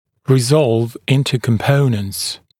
[rɪ’zɔlv ‘ɪntə kəm’pəunənts][ри’золв ‘интэ кэм’поунэнтс]раскладывать на компоненты (напр. о векторе)